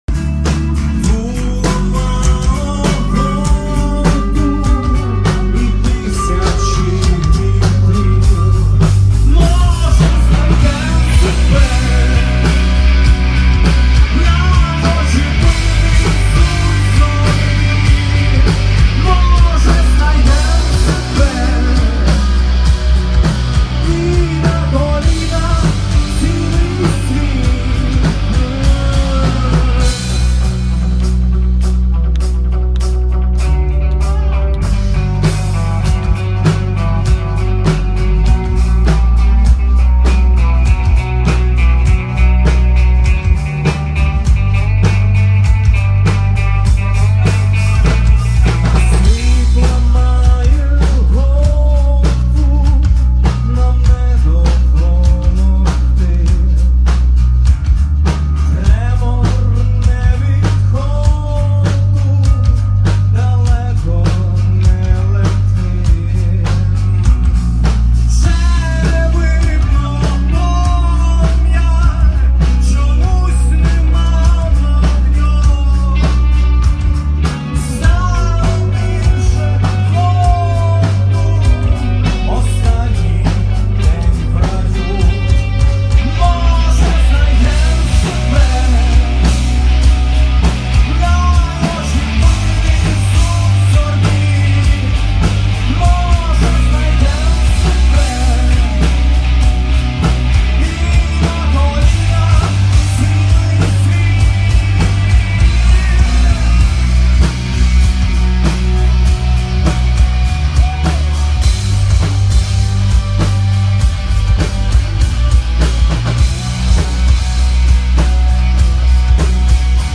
Темп: 100 BPM
Загальний стан: глуха безвихідь Ключовий елемент: гітара